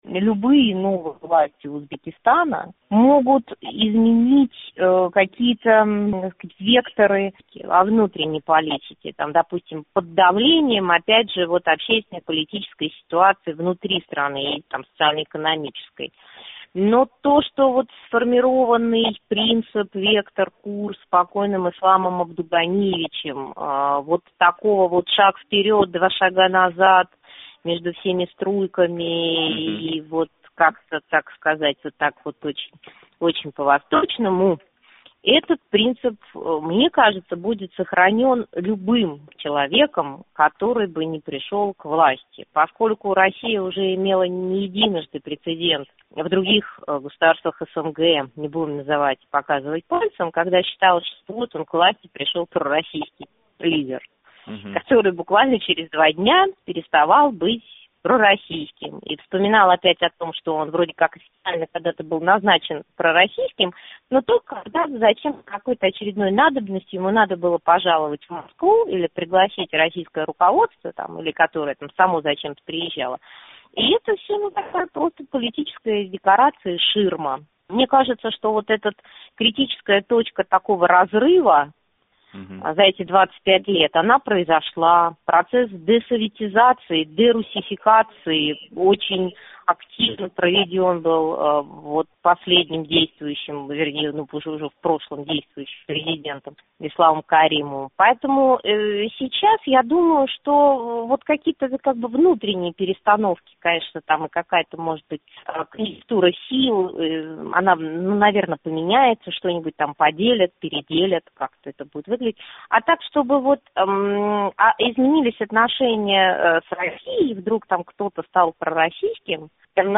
Алексей Малашенко билан суҳбат